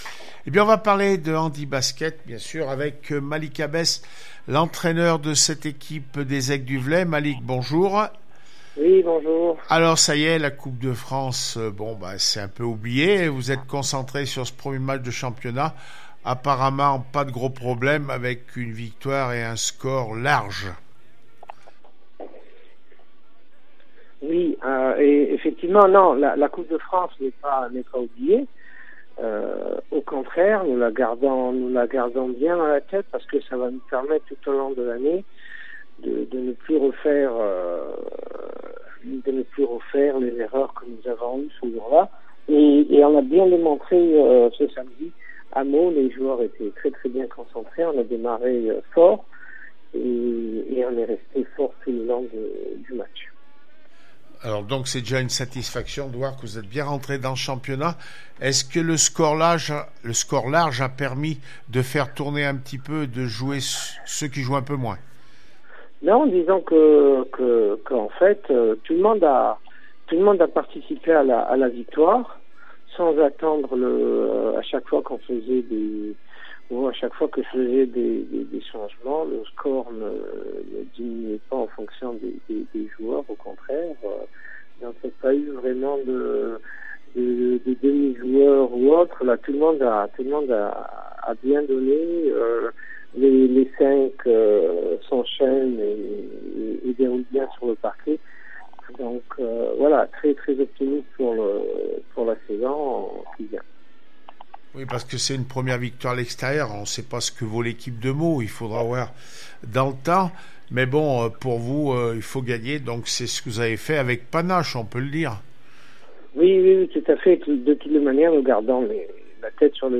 28 septembre 2020   1 - Sport, 1 - Vos interviews, 2 - Infos en Bref   No comments